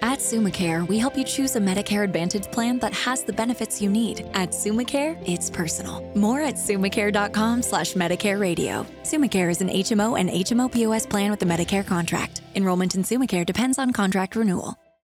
Benefits Radio Ad